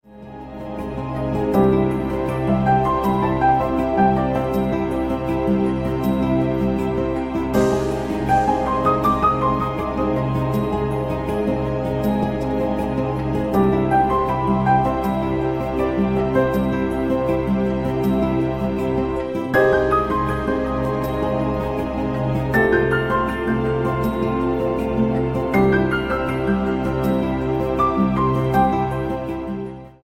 80 BPM
Arpeggiated piano over a lush pad.